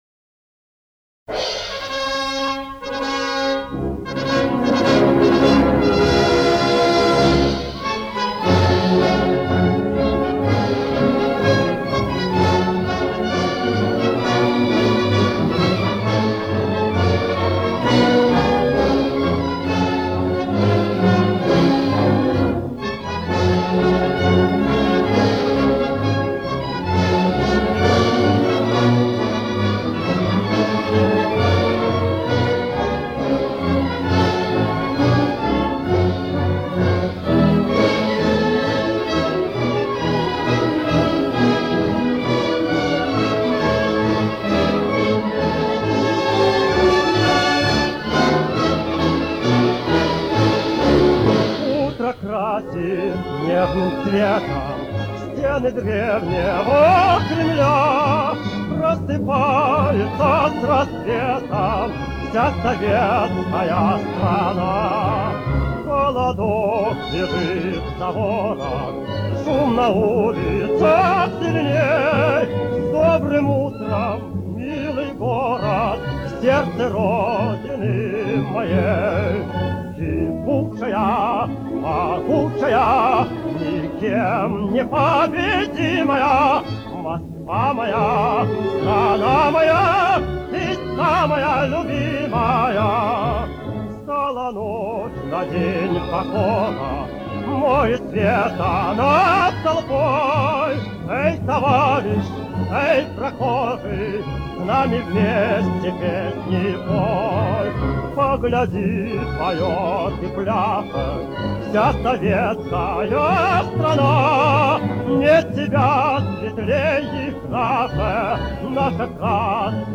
В песне звучит только первый, пятый куплет и припев.